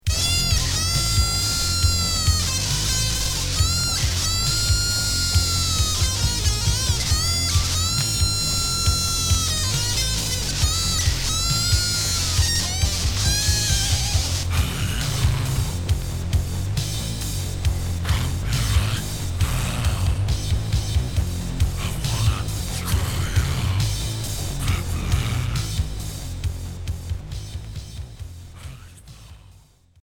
Punk synthétique expérimental Deuxième 45t